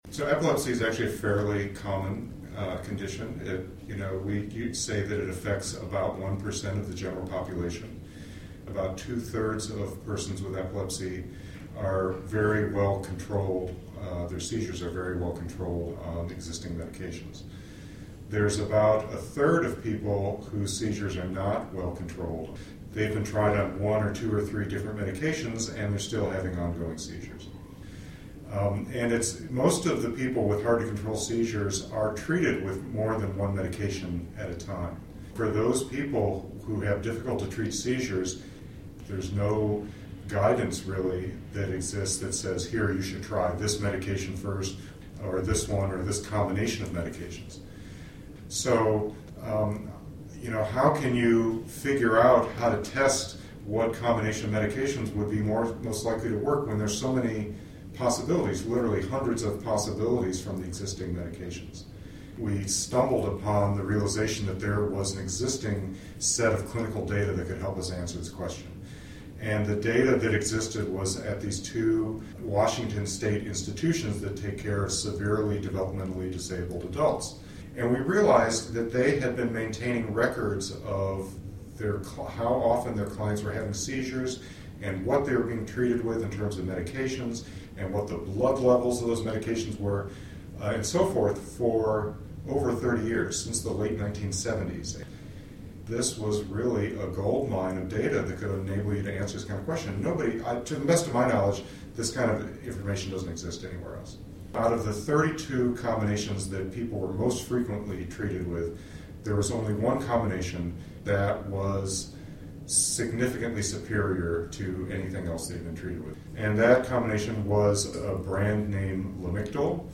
interview (.mp3).